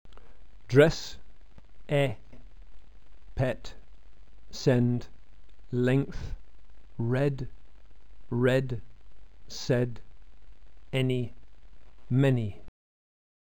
Lax vowels are always short